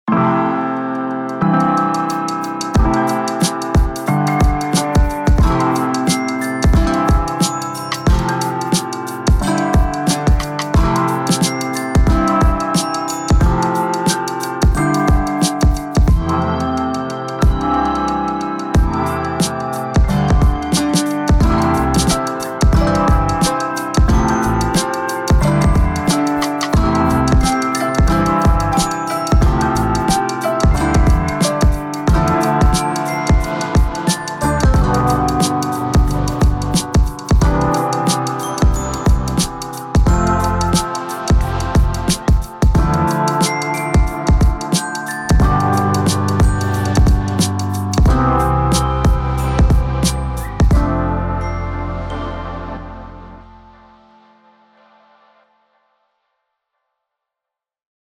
独特的键盘类音色，源自钢琴、管风琴、大键琴等乐器
结合了键盘采样与创意效果的混合式音色
钢琴、复音合成，不分彼此
这绝不是你见过的常规键盘类音源：HYBRID KEYS 混合了多种键盘采样、合成器、捶琴等等元素，并添加入强烈的效果器，创造出了全新的声音。